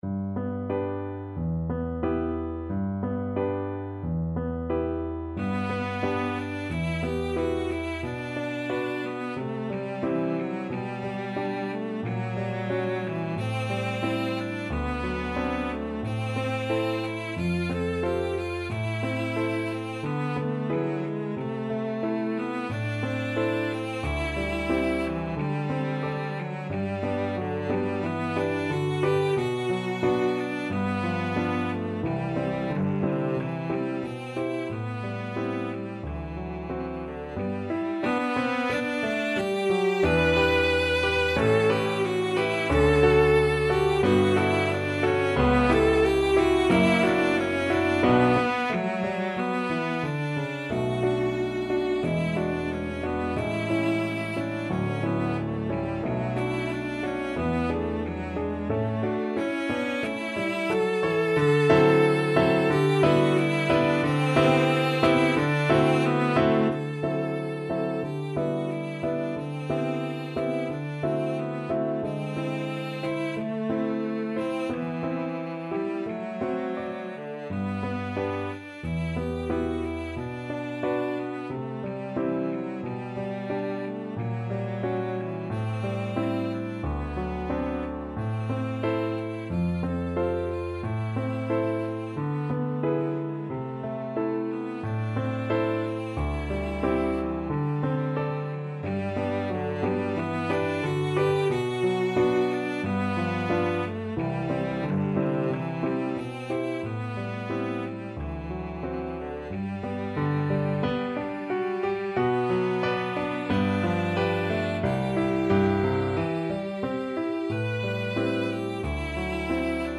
Cello
G major (Sounding Pitch) (View more G major Music for Cello )
~ = 100 Allegretto con moto =90
2/4 (View more 2/4 Music)
Classical (View more Classical Cello Music)